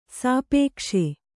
♪ sāpēkṣe